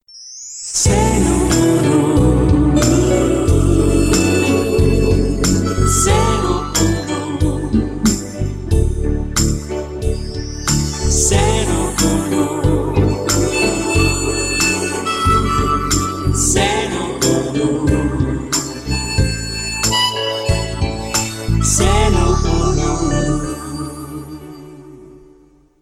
Indicatiu nocturn de l'emissora